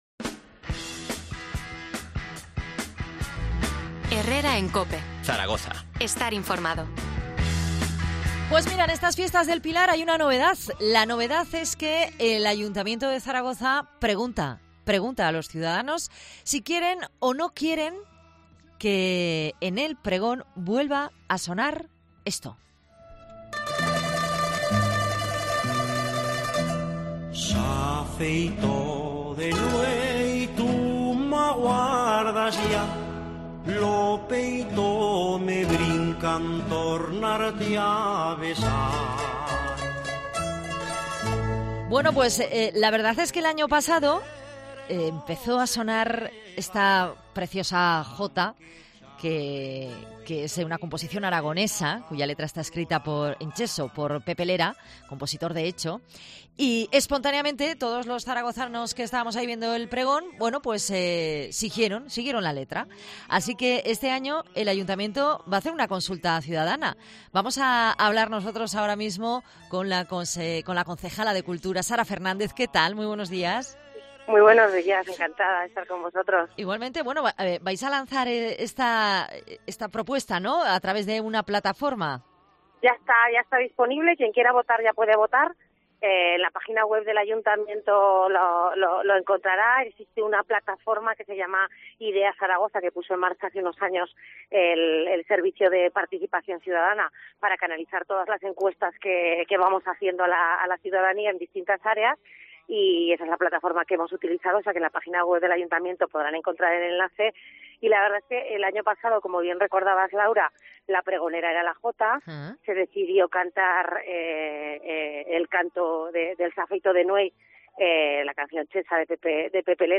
AUDIO: Entrevista a la consejera de Cultura, Sara Fernández, sobre el 'S’ha Feito de Nuey' en el Pregón.